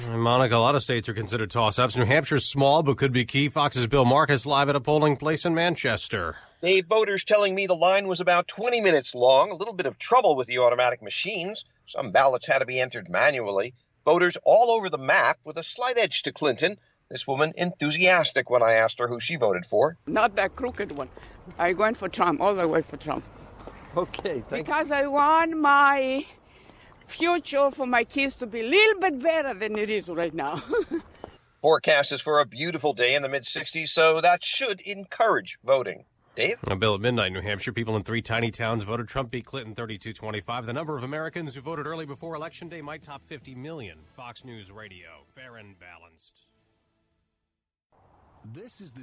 (MANCHESTER, NH) 8AM LIVE –